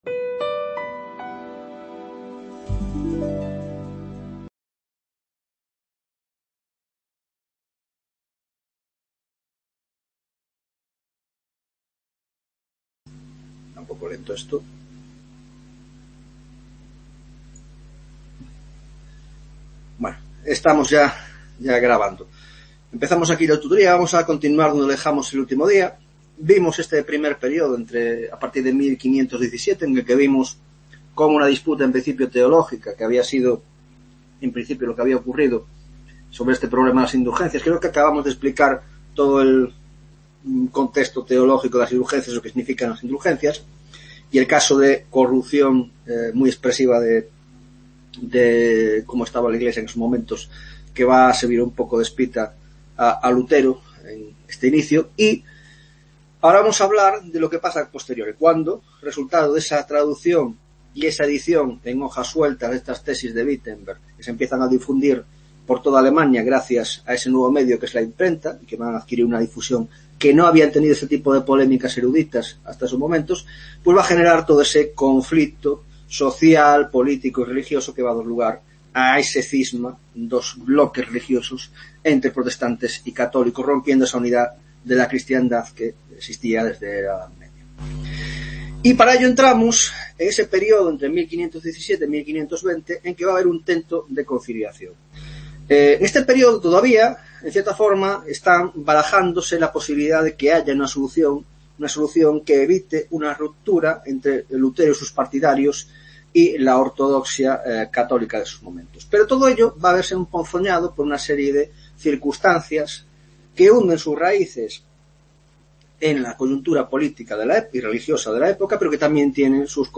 7 Tutoria de la asignatura de Historia Moderna, Grado de Antropología: Reforma Protestante y Ruptura de la Cristiandad (2ª parte)